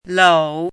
chinese-voice - 汉字语音库
lou3.mp3